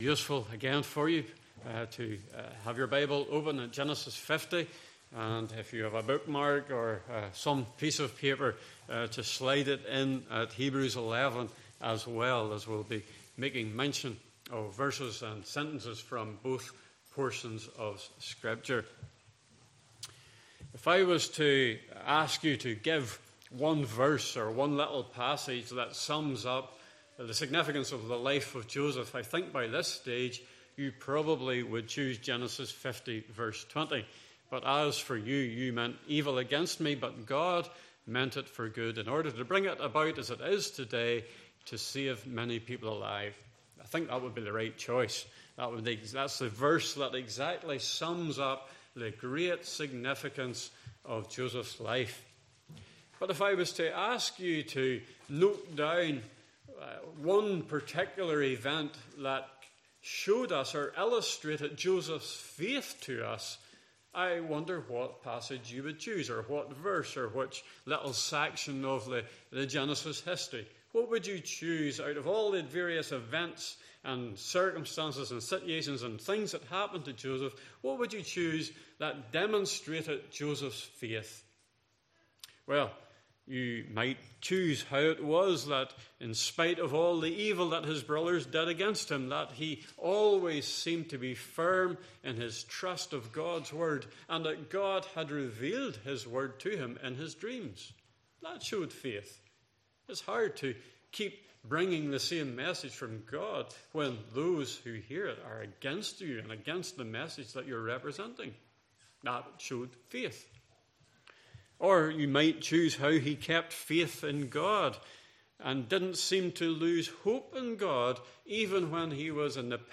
Passage: Genesis 50:15-26 Service Type: Morning Service